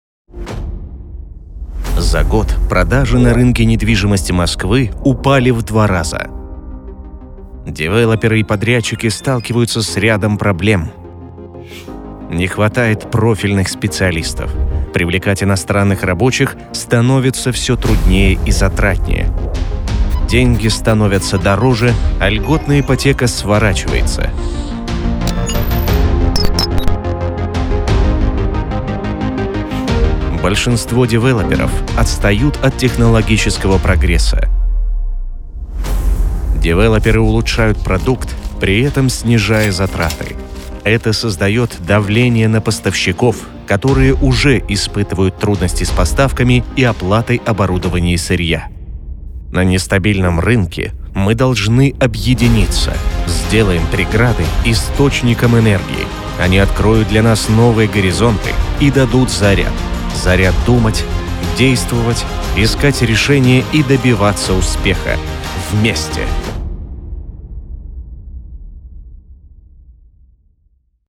Запись диктора, монтаж музыки, звуковые эффекты
Аудио презентация для видео со звуковым дизайном
Так может звучать ваша презентация